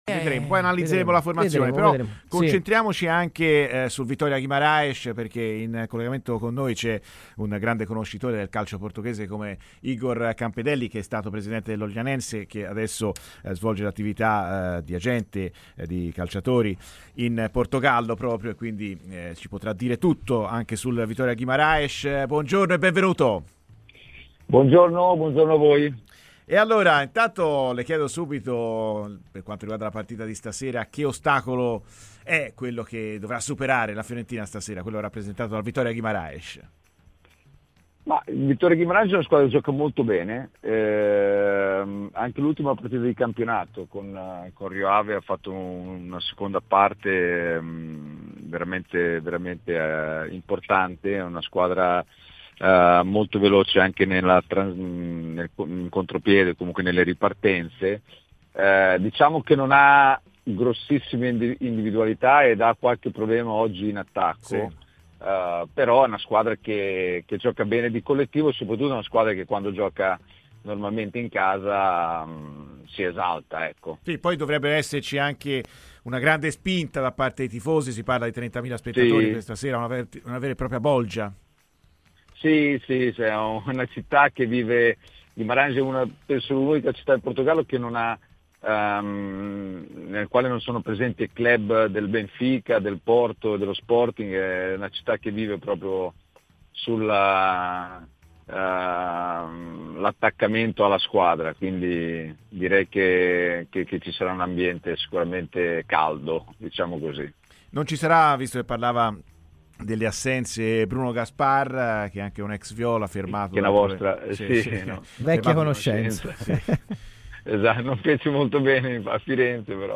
ha parlato così ai microfoni di Radio FirenzeViola